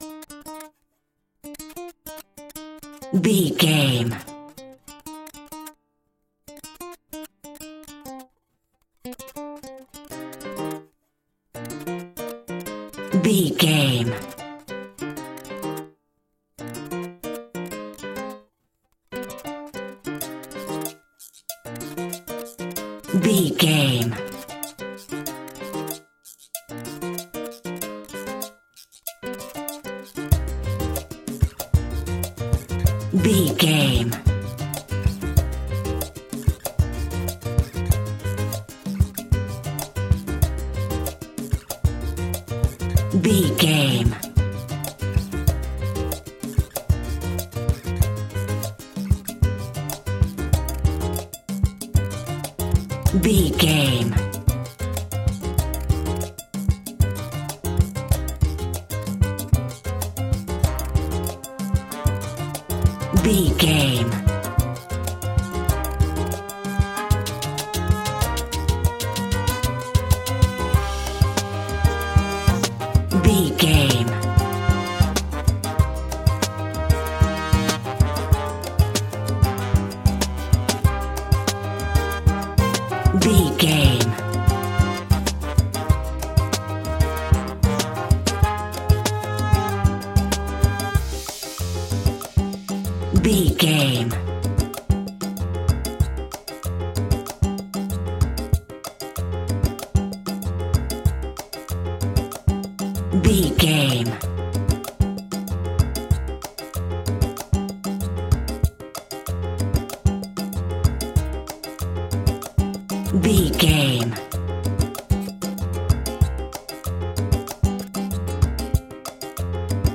Aeolian/Minor
flamenco
salsa
maracas
percussion spanish guitar